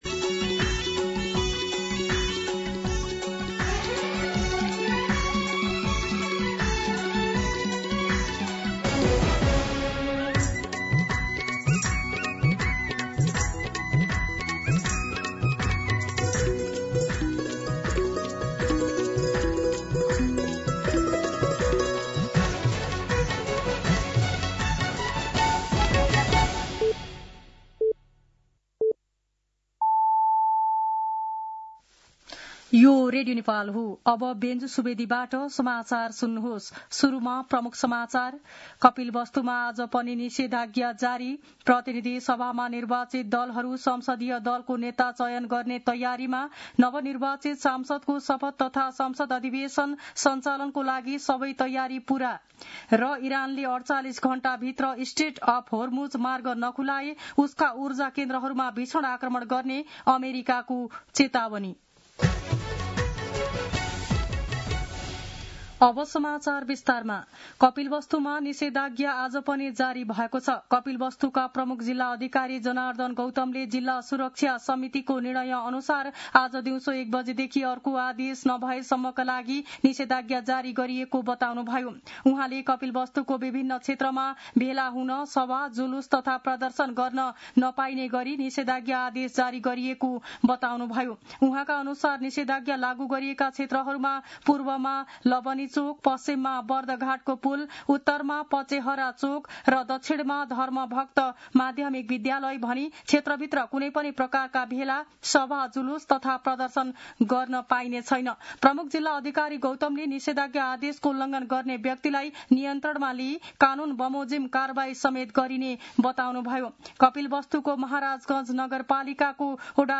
दिउँसो ३ बजेको नेपाली समाचार : ८ चैत , २०८२
3pm-Nepali-News-2.mp3